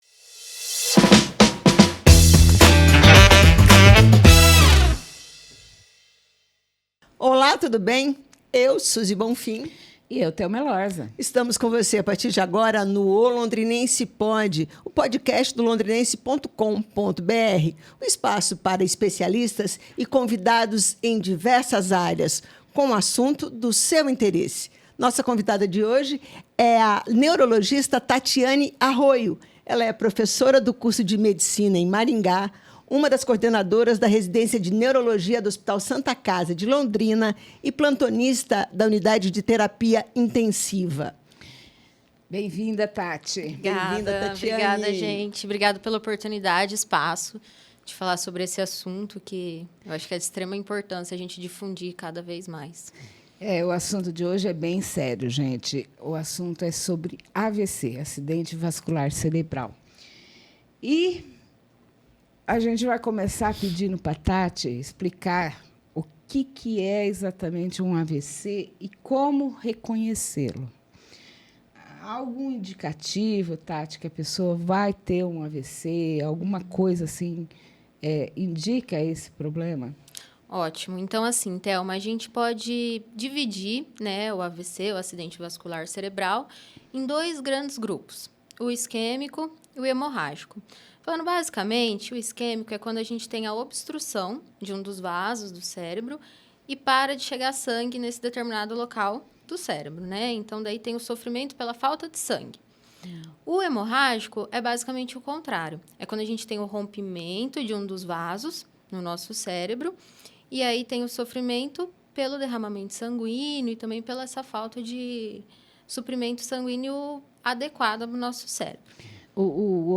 O AVC é a segunda maior causa de mortes no mundo. Nesta entrevista, ela fala dos sintomas e ensina como a palavra SAMU é o caminho para o socorro rápido de quem tem um Acidente Vascular Cerebral.